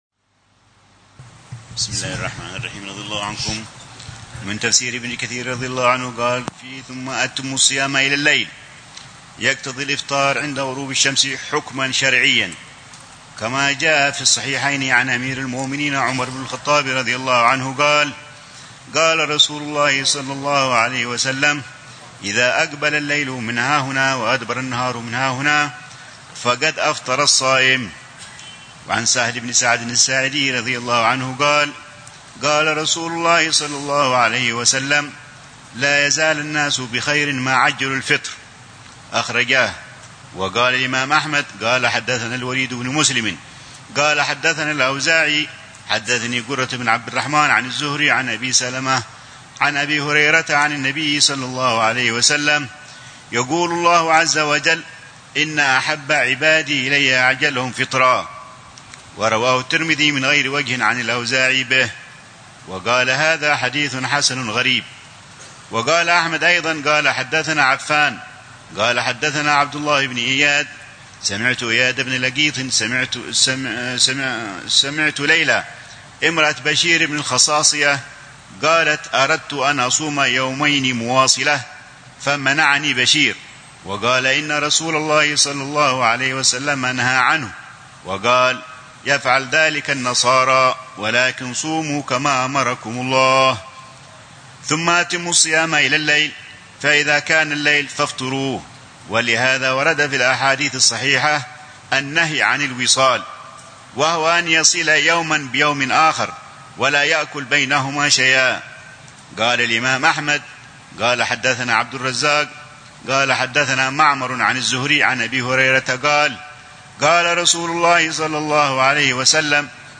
دروس في الروحة الرمضانية التي يلقيها الحبيب عمر يومياً بدار المصطفى وتتضمن القراءة في الكتب التالية: تفسير ابن كثير لآيات الصيام، كتاب الصيام